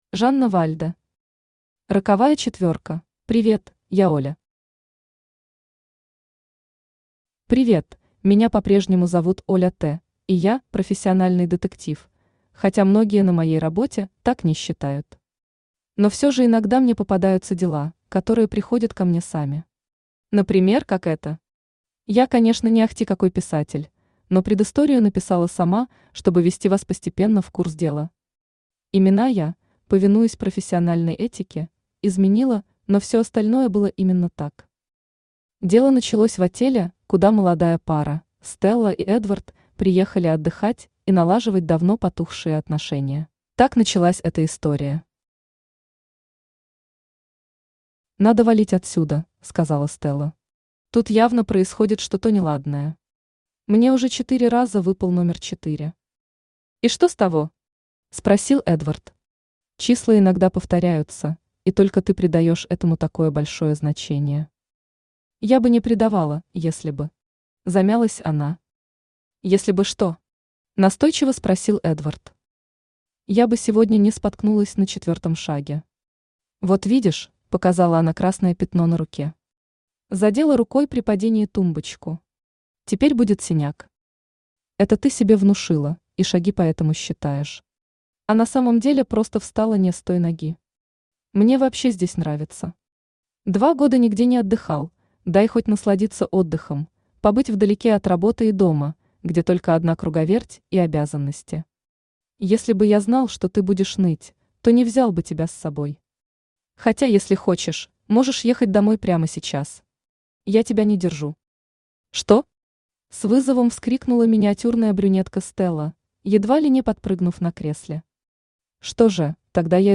Аудиокнига Роковая четверка | Библиотека аудиокниг
Aудиокнига Роковая четверка Автор Жанна Вальда Читает аудиокнигу Авточтец ЛитРес.